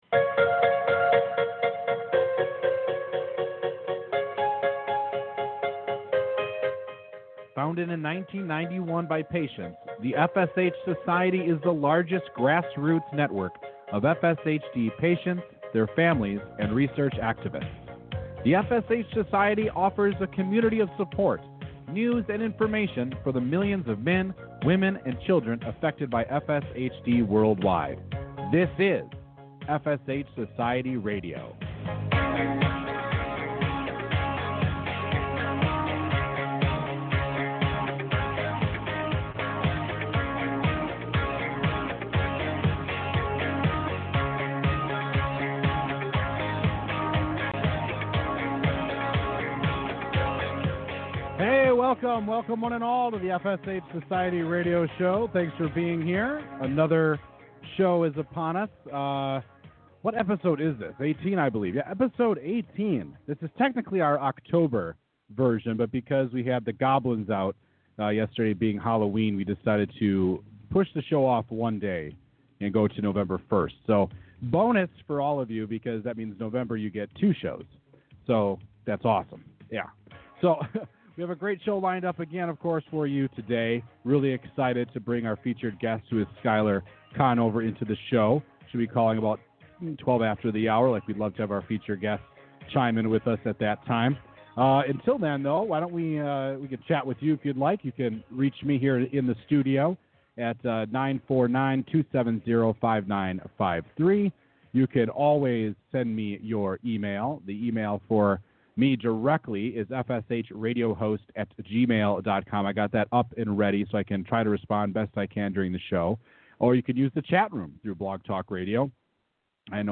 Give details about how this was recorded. live interview